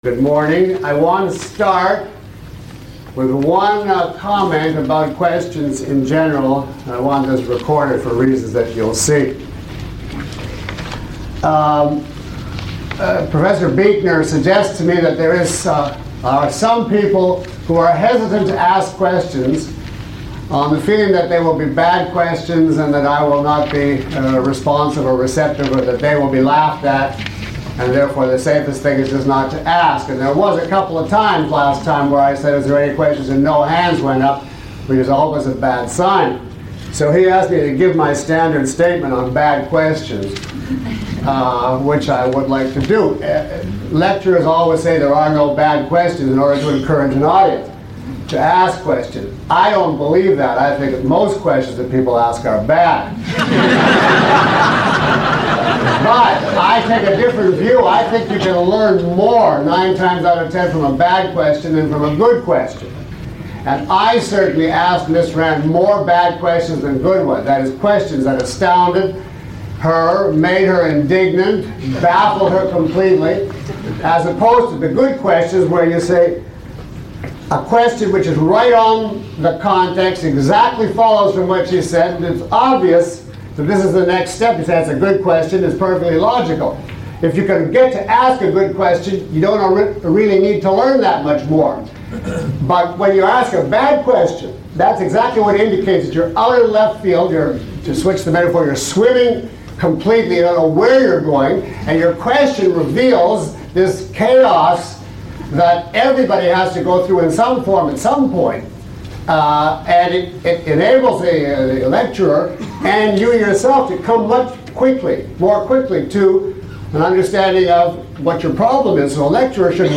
Lecture 03 - The Art of Thinking.mp3